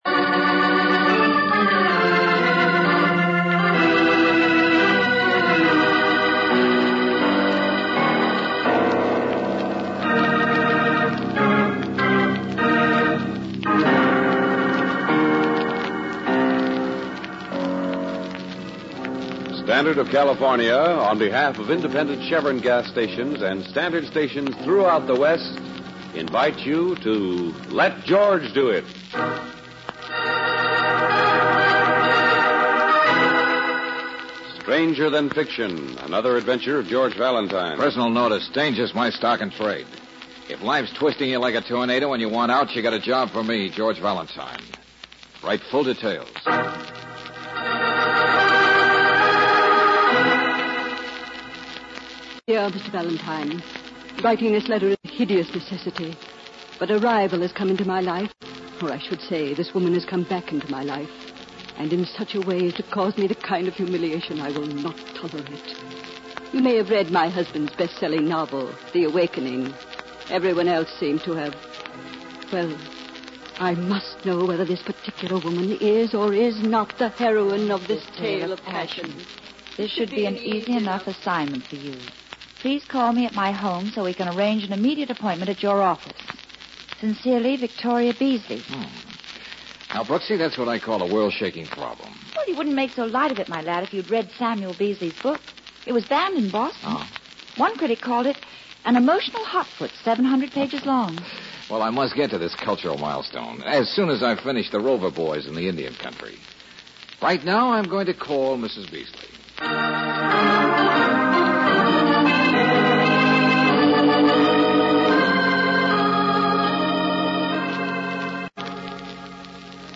Let George Do It Radio Program